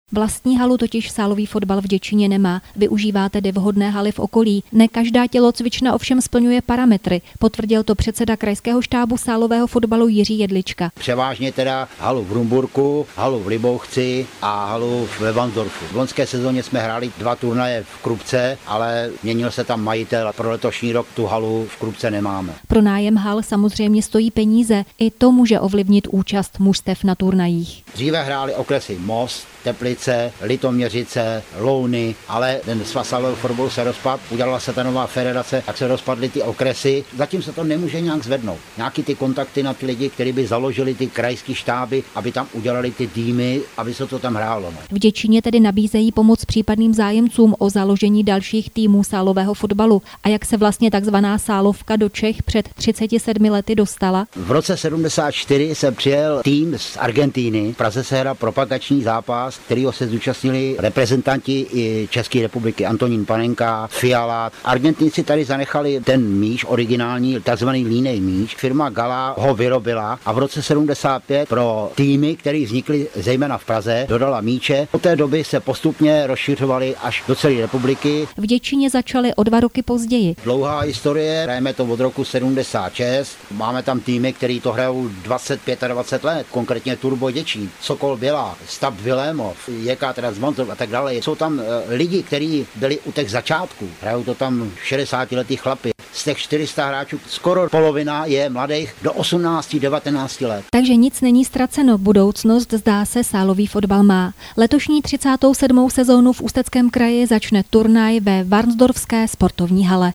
rozhovor01.mp3